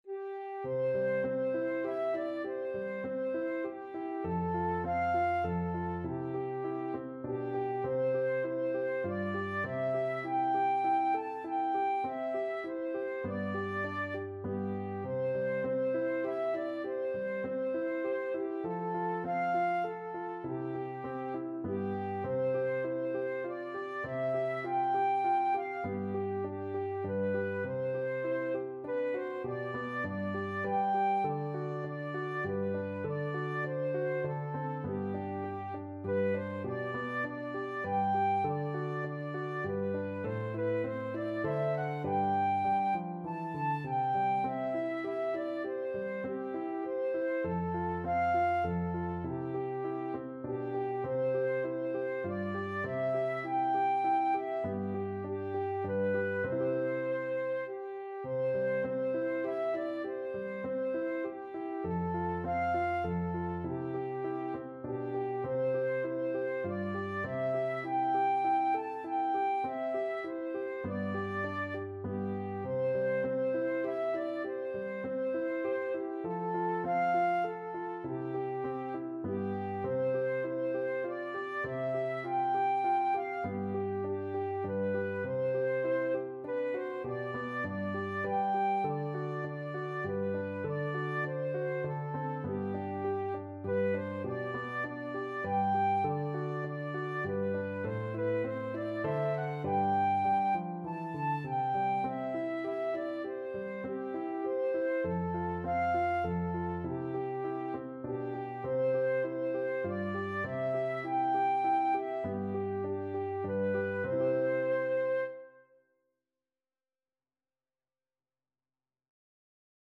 Flute
Traditional Music of unknown author.
C major (Sounding Pitch) (View more C major Music for Flute )
Moderato
3/4 (View more 3/4 Music)
G5-A6
Classical (View more Classical Flute Music)